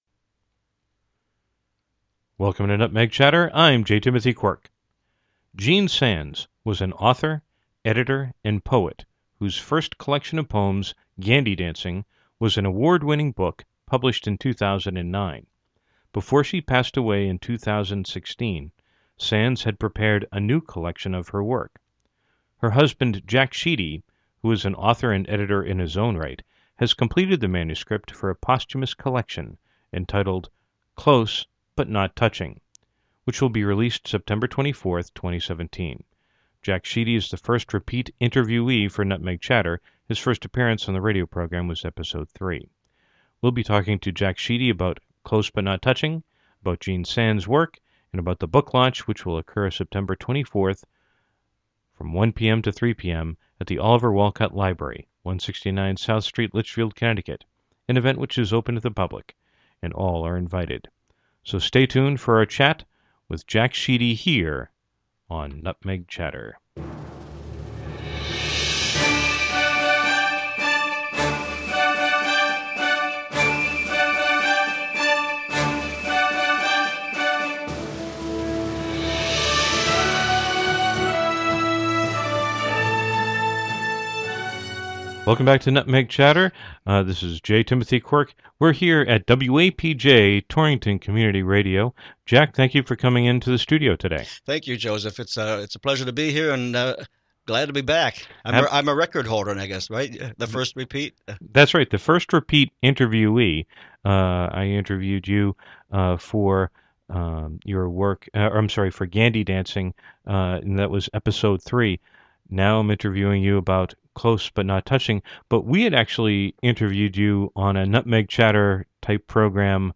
Categories: Radio Show, Torrington Stories